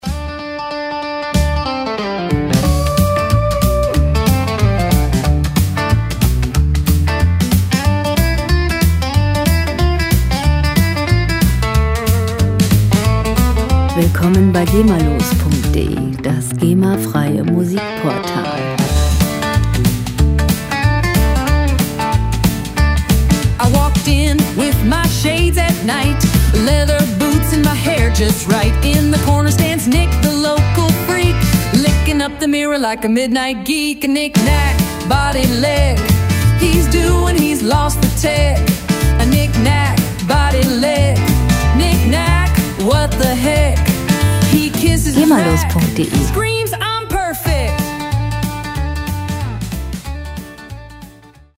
Musikstil: Country & Western
Tempo: 185 bpm
Tonart: D-Dur
Charakter: nostalgisch, zeitlos